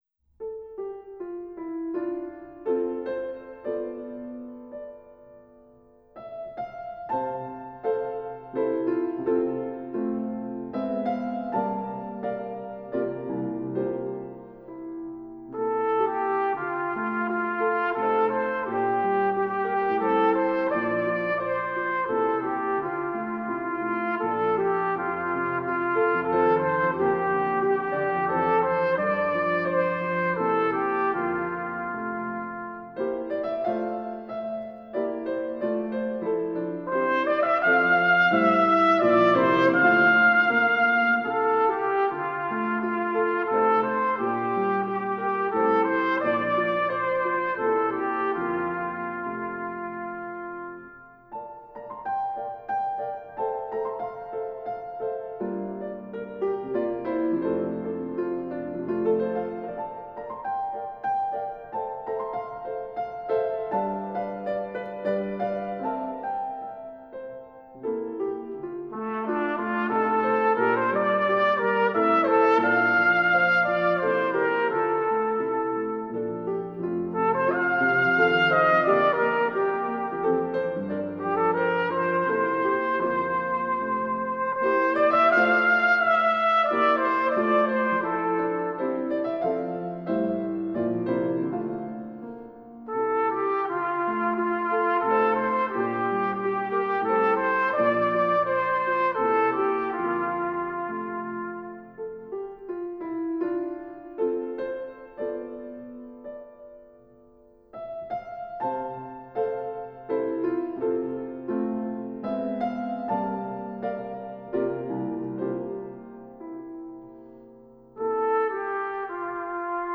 SOLO BRASS
Demonstration Recording